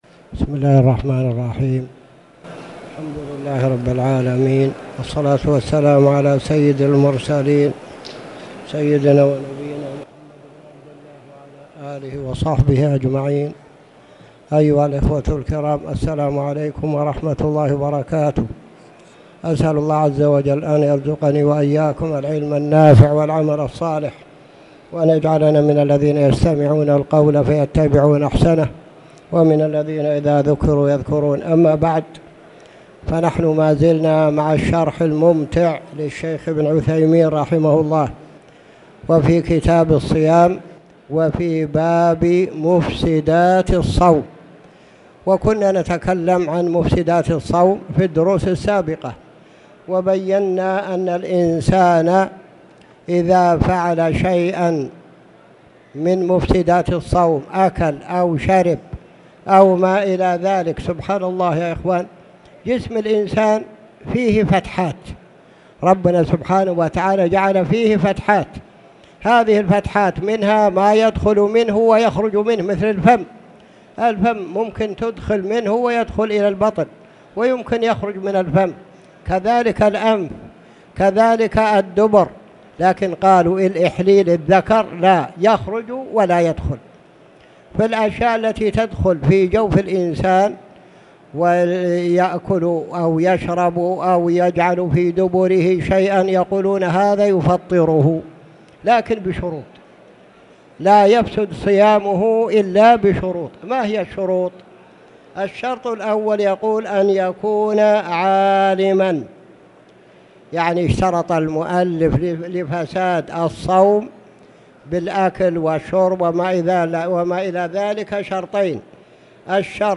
تاريخ النشر ٢٧ رجب ١٤٣٨ هـ المكان: المسجد الحرام الشيخ